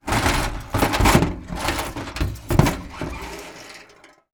Metal_06.wav